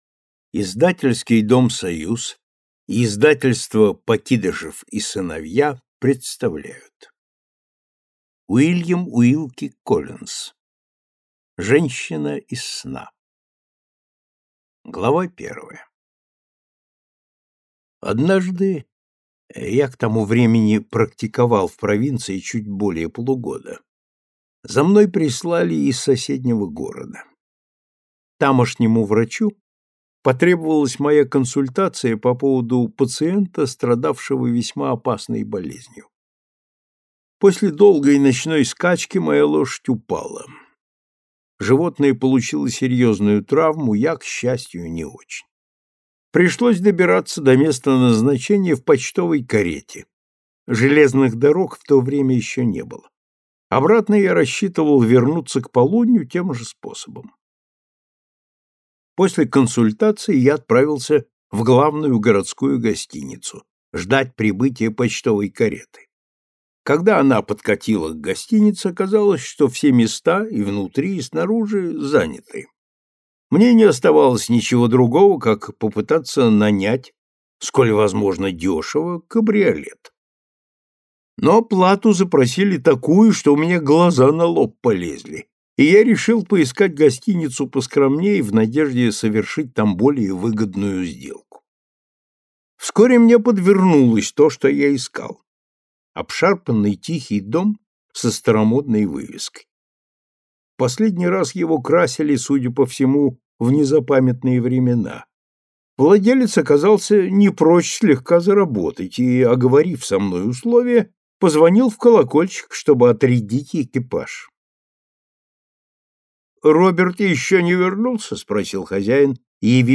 Аудиокнига Женщина из сна | Библиотека аудиокниг